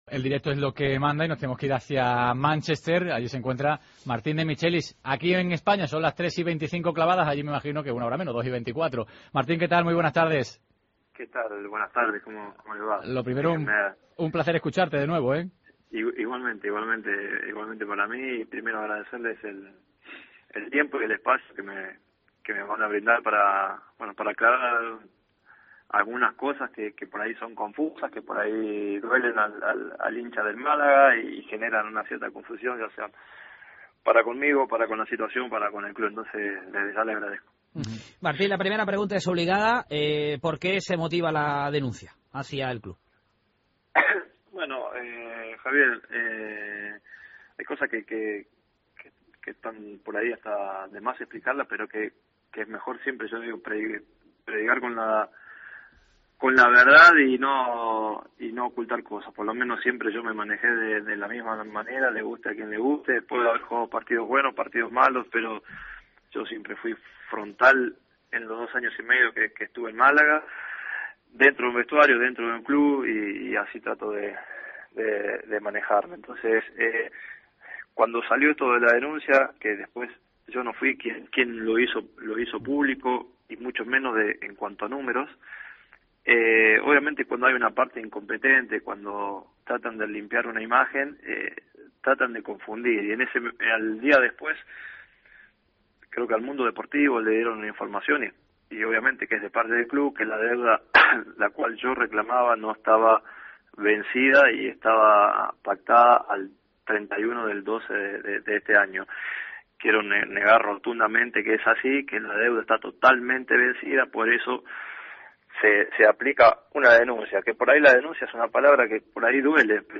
Entrevista con Martin Demichelis